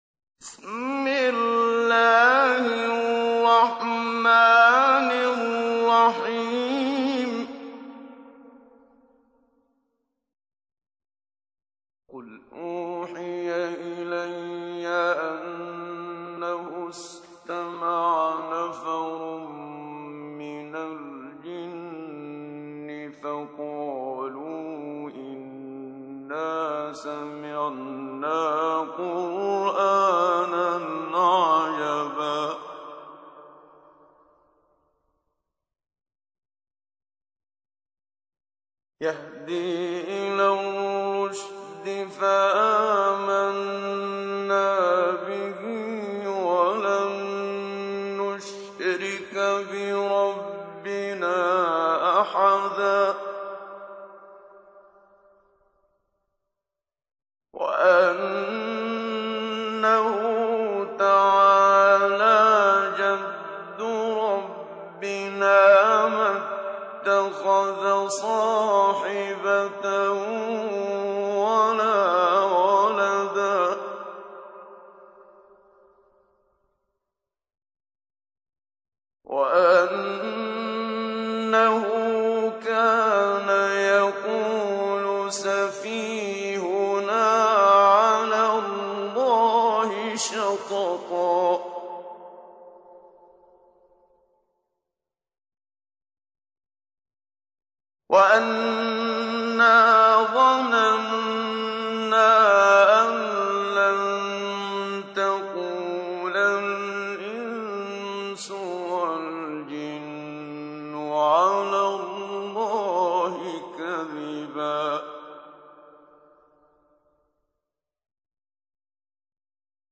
تحميل : 72. سورة الجن / القارئ محمد صديق المنشاوي / القرآن الكريم / موقع يا حسين